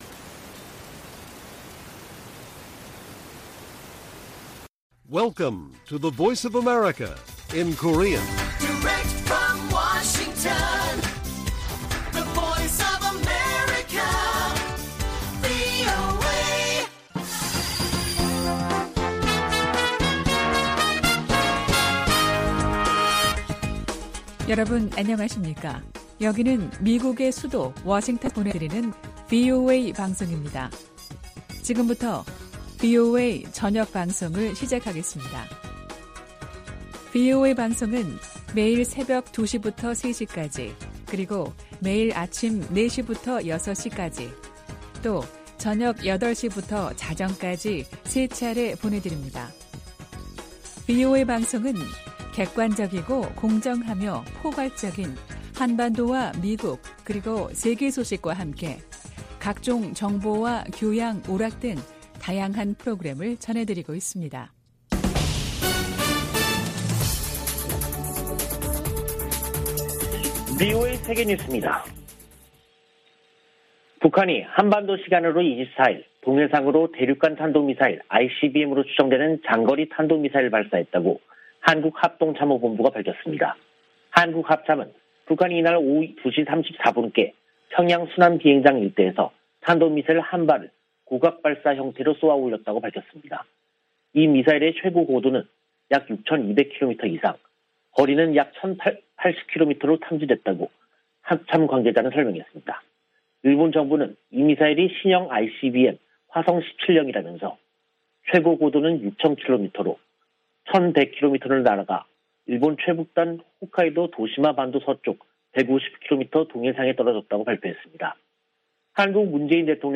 VOA 한국어 간판 뉴스 프로그램 '뉴스 투데이', 2022년 3월 24일 1부 방송입니다. 북한이 24일 대륙간탄도미사일(ICBM)으로 추정되는 미사일을 발사했습니다.